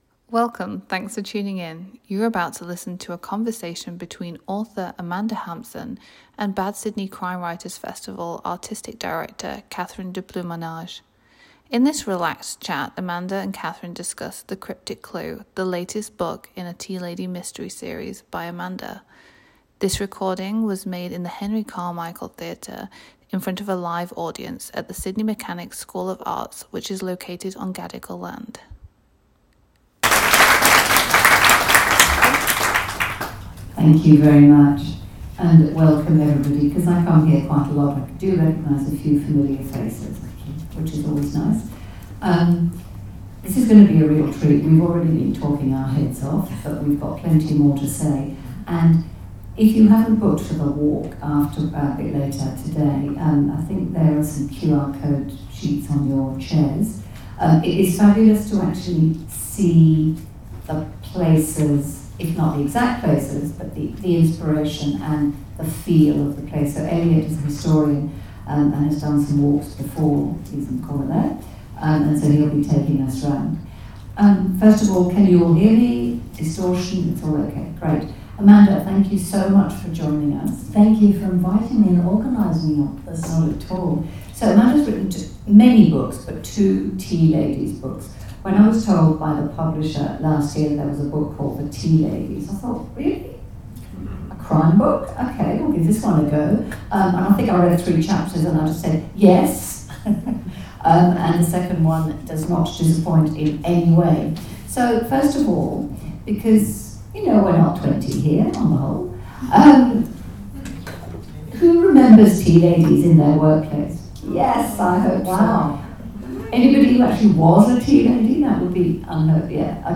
EVENT RECORDING